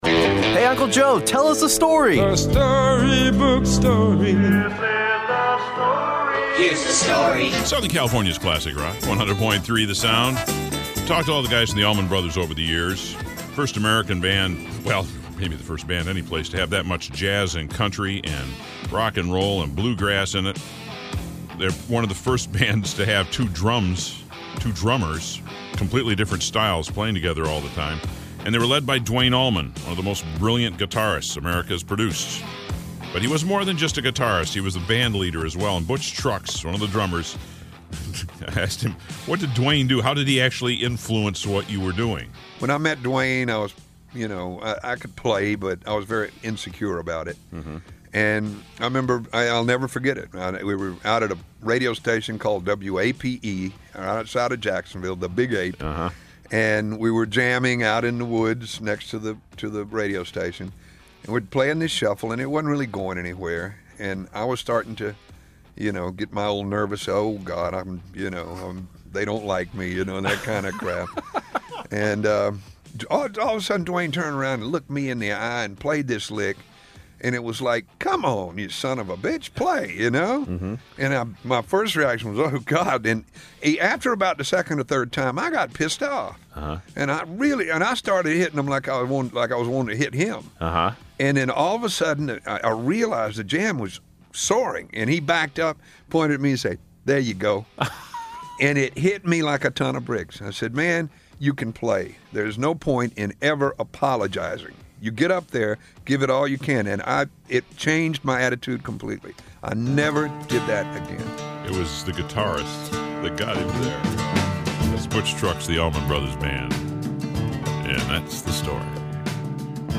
Allman Brothers Band drummer Butch Trucks shares the story about how Duane Allman influenced his drumming.